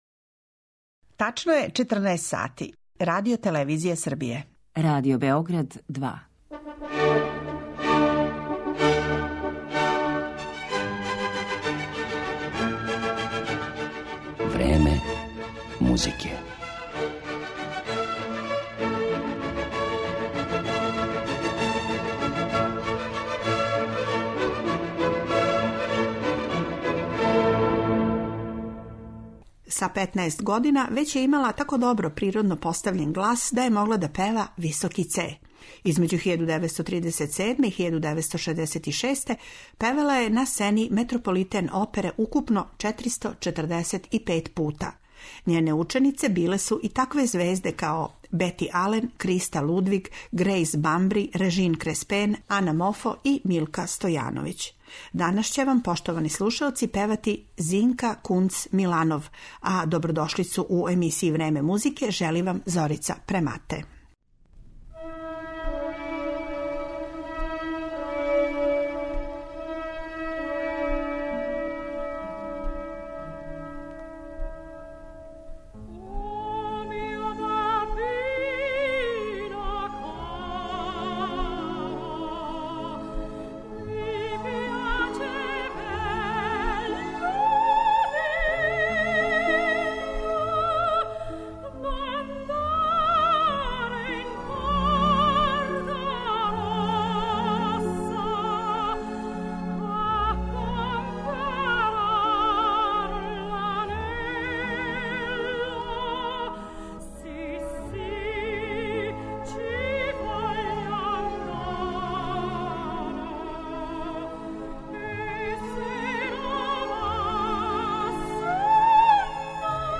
Певаће вам Зинка Кунц Миланов